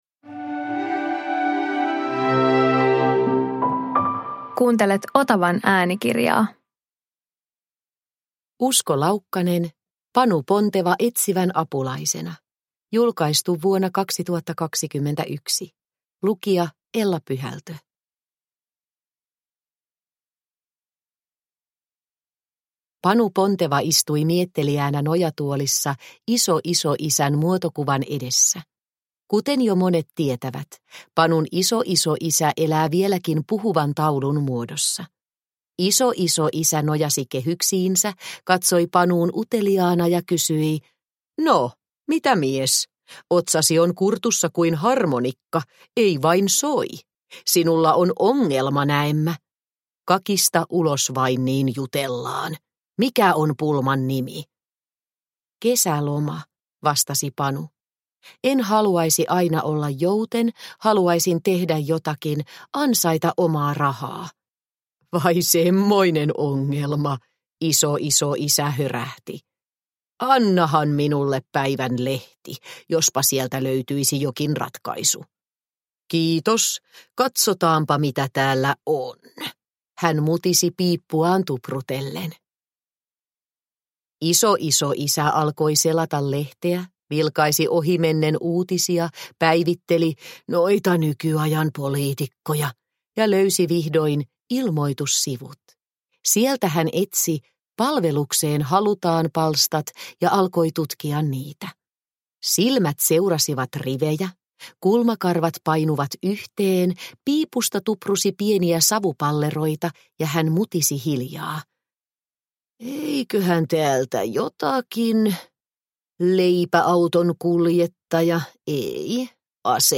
Panu Ponteva etsivän apulaisena – Ljudbok – Laddas ner